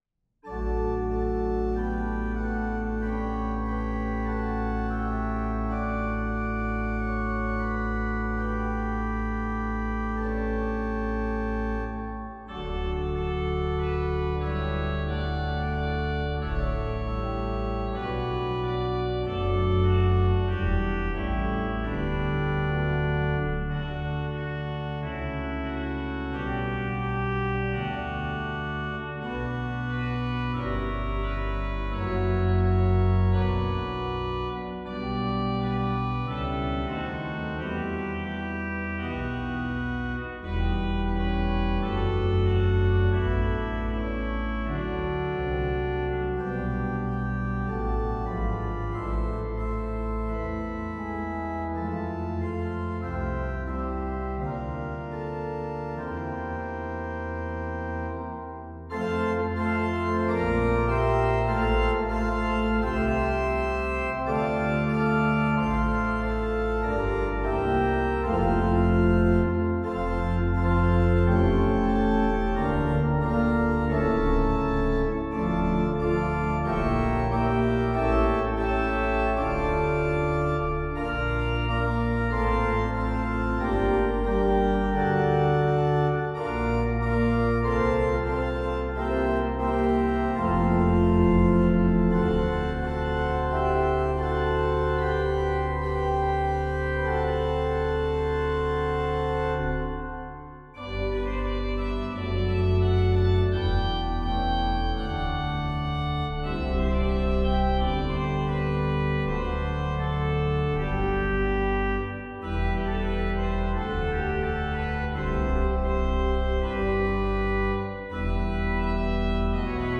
Voicing: Organ 2-staff